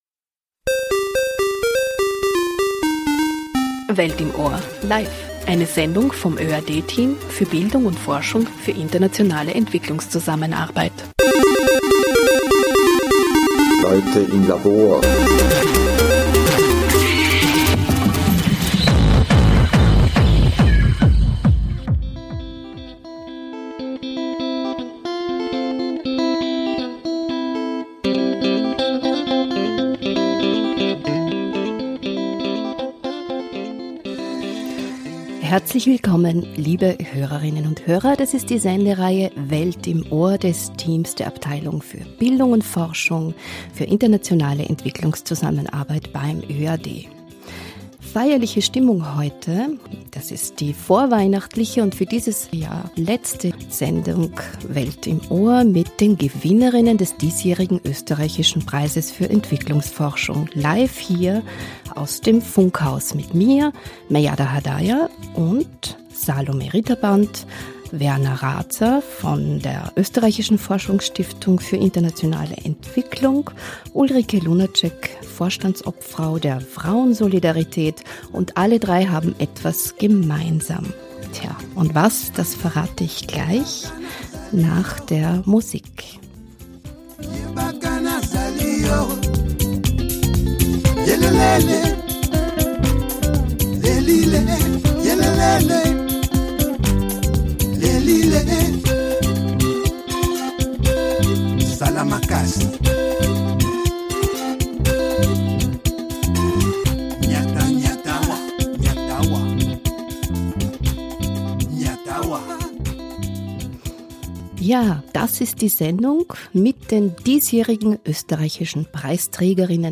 Die Preisträger/innen des diesjährigen Österreichischen Preises für Entwicklungsforschung im Gespräch bei Welt im Ohr.